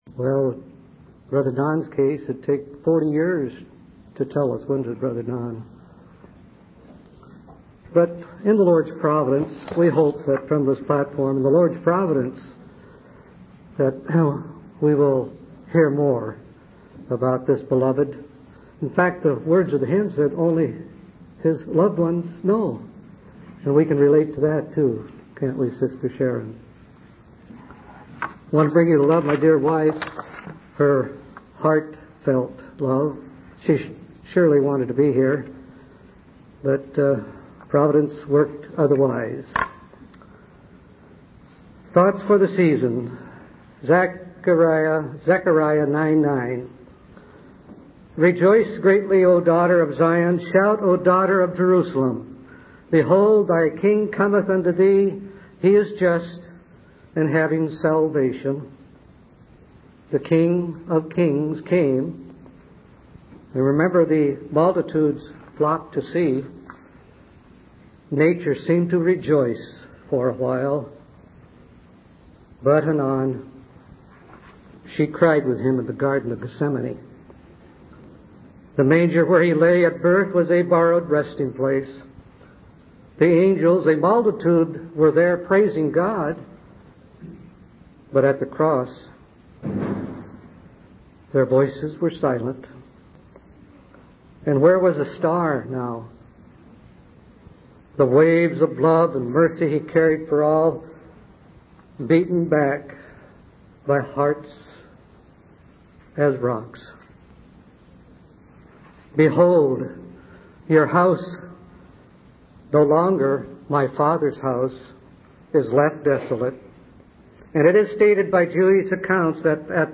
From Type: "Discourse"
North Seattle Convention 1995